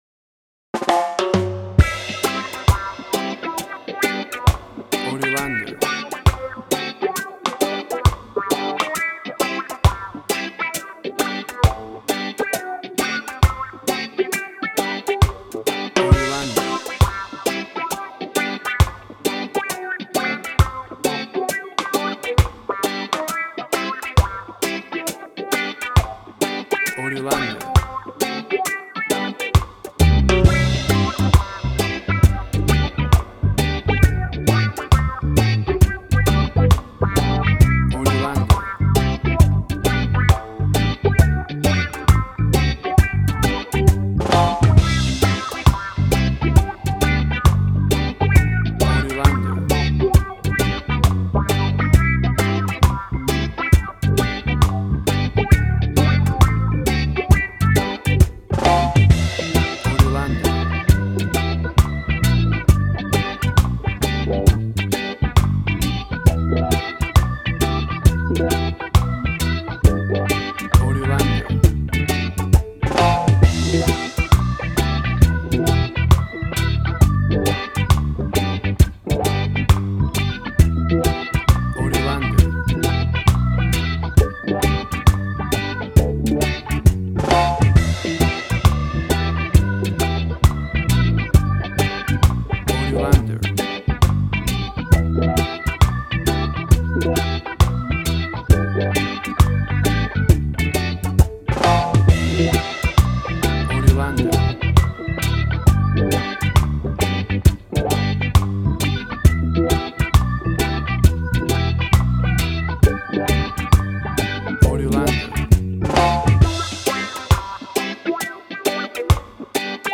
Tempo (BPM): 67